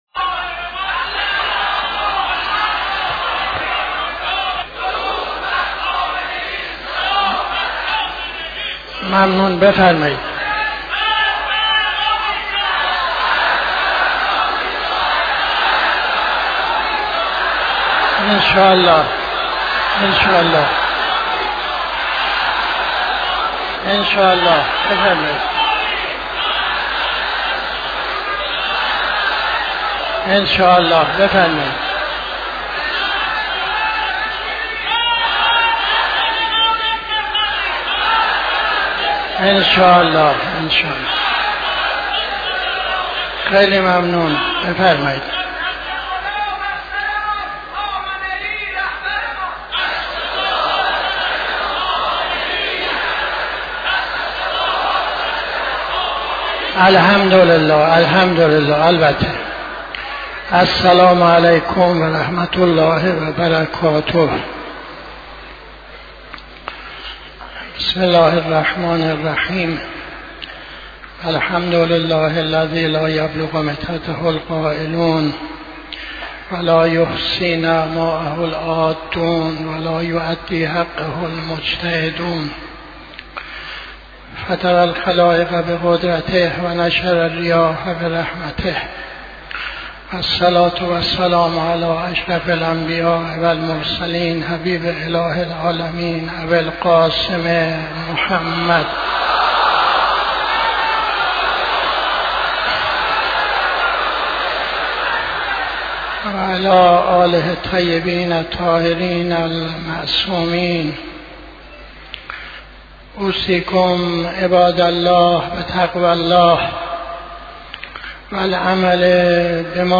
خطبه اول نماز جمعه 09-01-82